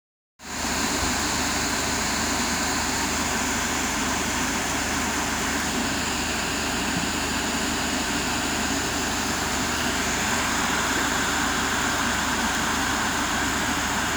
• Качество: 320, Stereo